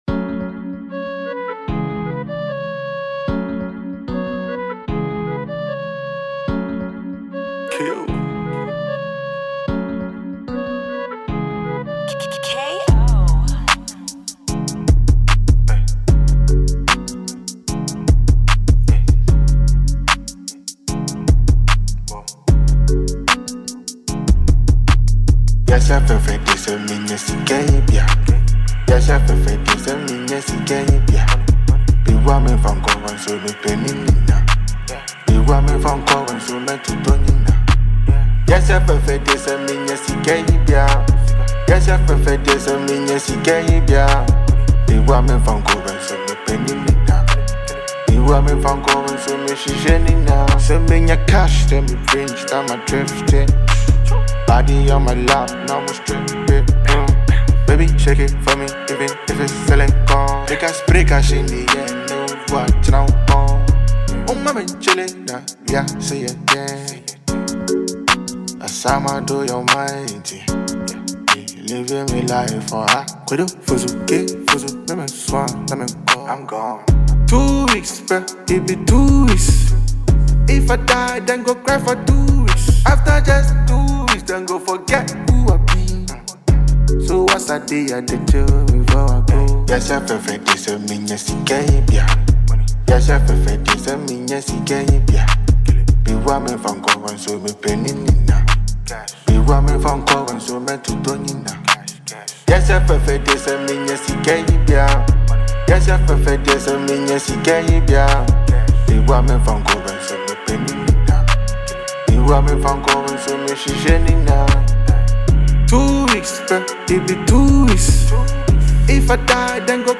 Genre: Hiplife